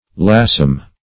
Search Result for " laughsome" : The Collaborative International Dictionary of English v.0.48: Laughsome \Laugh"some\, a. Exciting laughter; also, addicted to laughter; merry.